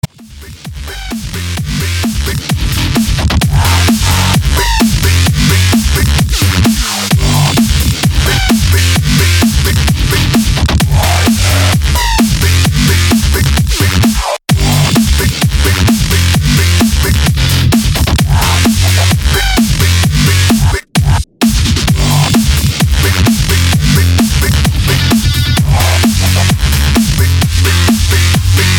Elektronisk musik